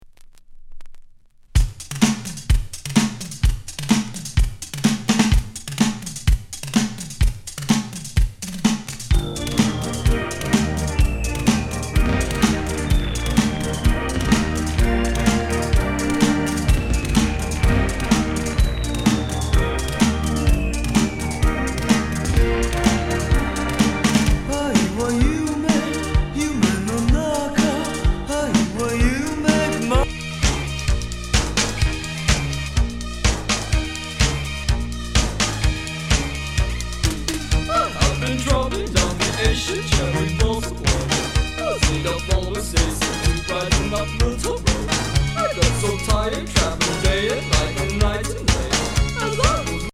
耽美シンセ・ポップ・グループ83年作。
変ディスコNW